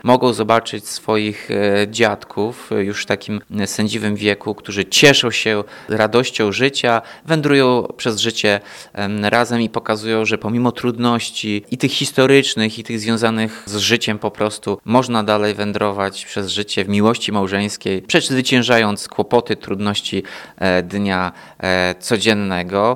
– To wyjątkowe święto dla dzieci, wnuków i prawnuków – mówi Tomasz Andrukiewicz, prezydent Ełku.